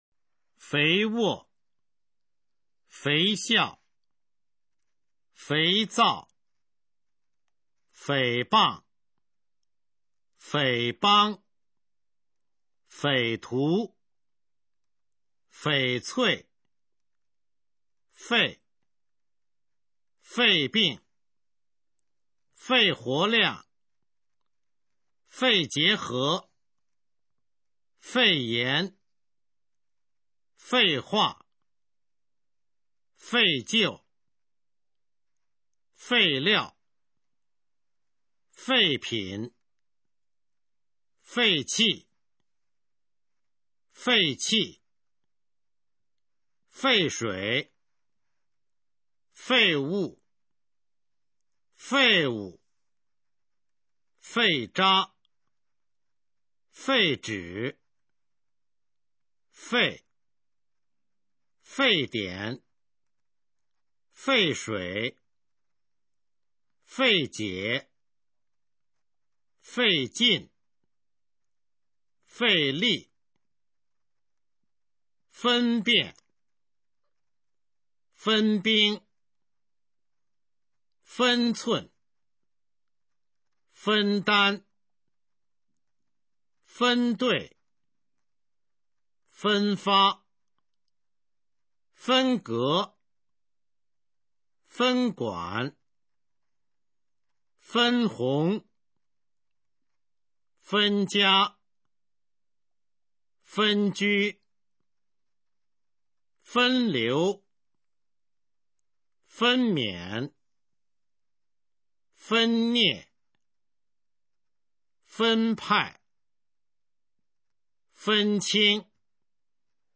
首页 视听 学说普通话 美声欣赏